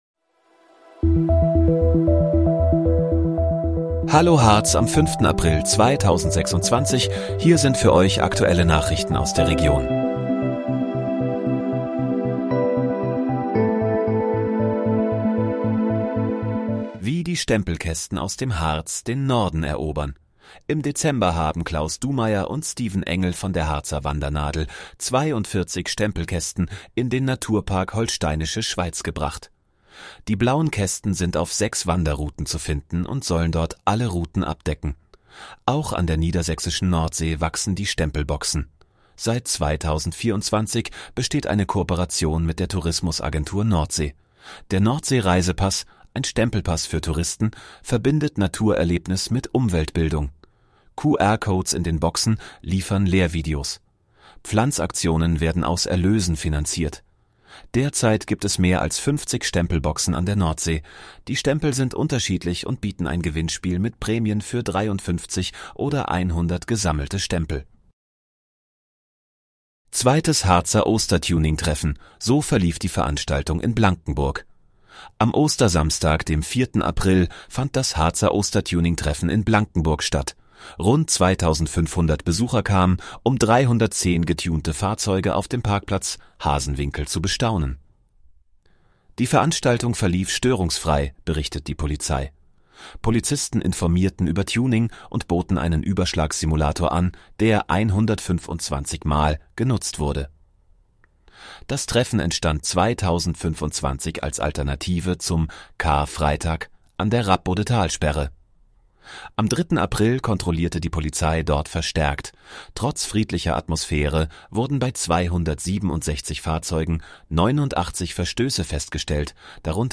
Hallo, Harz: Aktuelle Nachrichten vom 05.04.2026, erstellt mit KI-Unterstützung